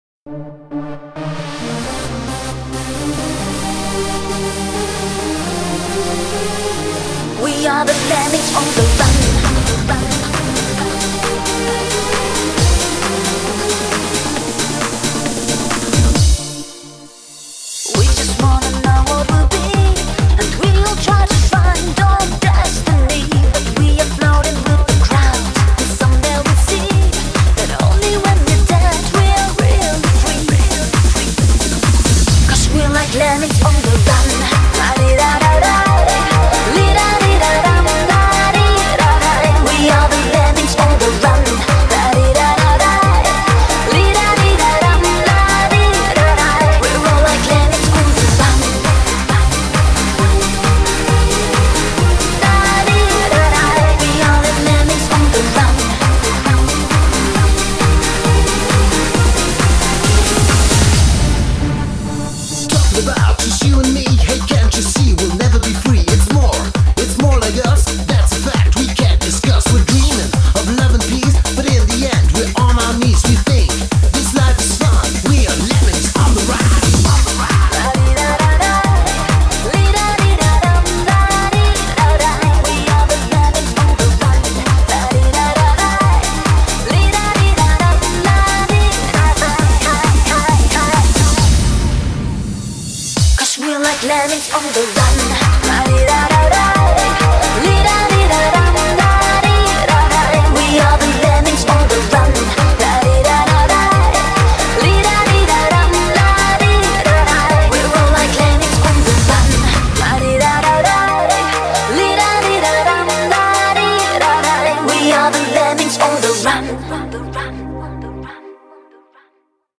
BPM134--1
Audio QualityPerfect (High Quality)